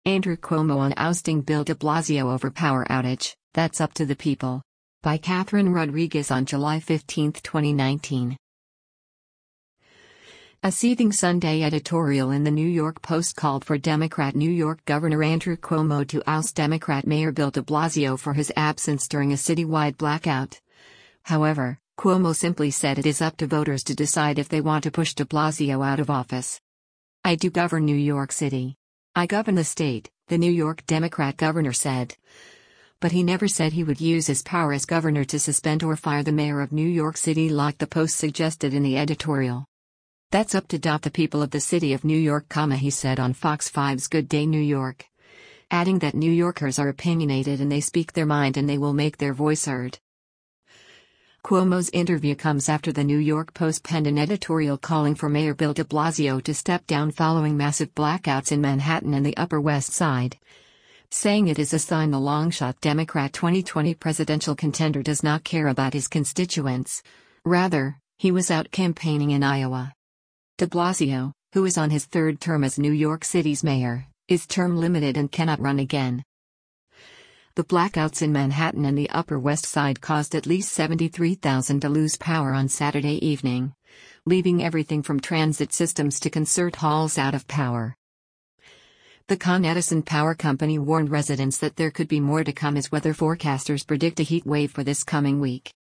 “That’s up to … the people of the city of New York,” he said on Fox 5’s Good Day New York, adding that “New Yorkers are opinionated and they speak their mind and they will make their voice heard.”